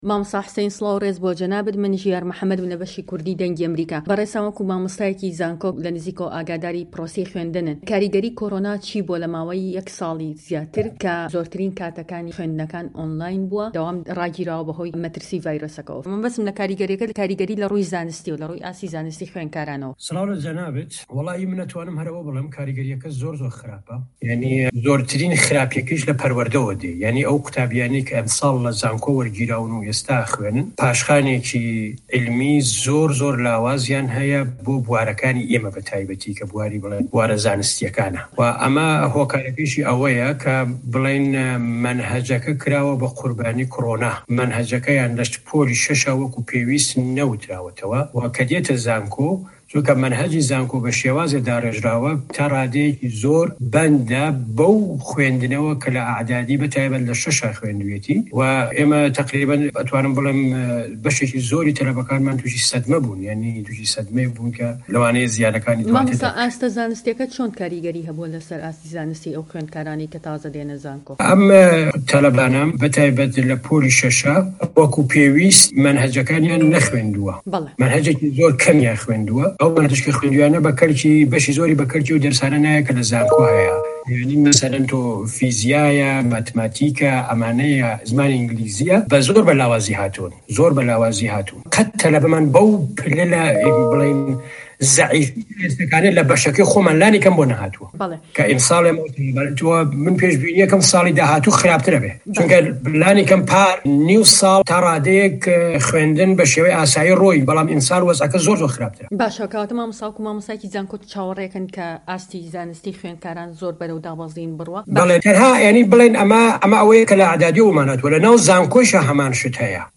دەقی وتووێژەکەی